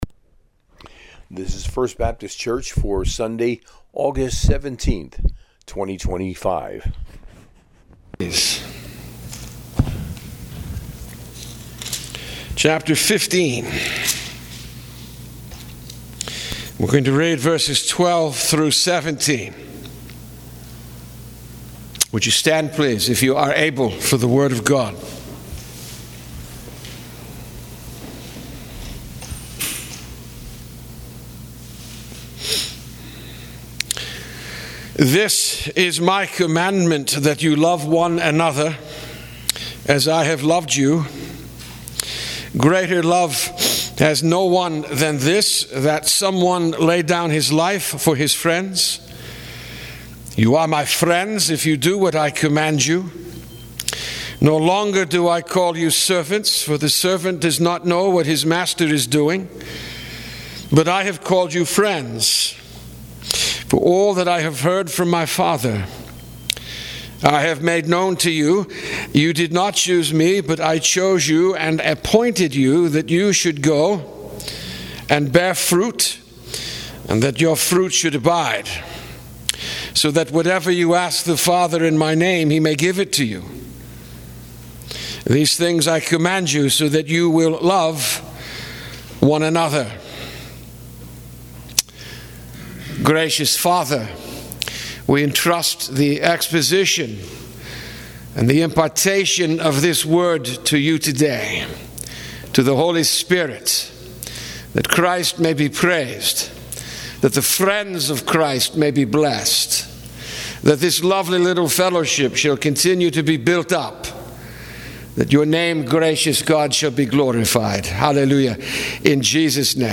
Sunday Sermon, taken from Gospel of John 15:12-17